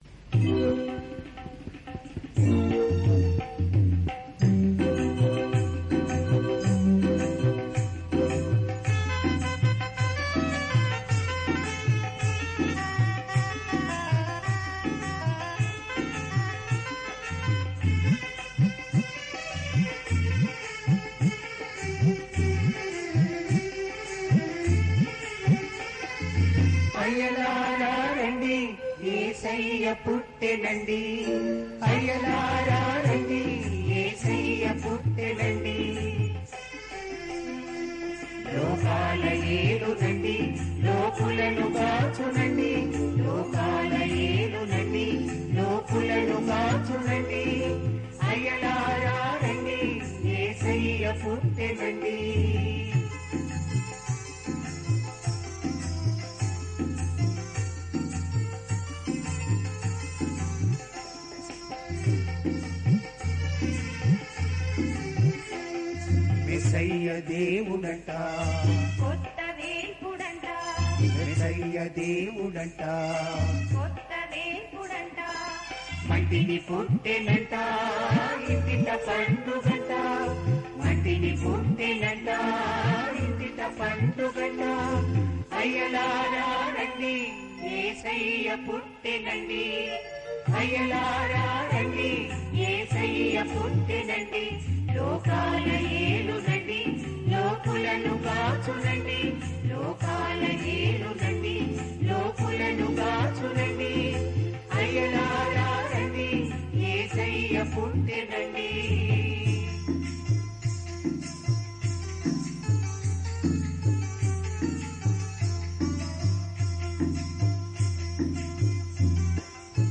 Ragam - sankarabharanam
Singer - chorus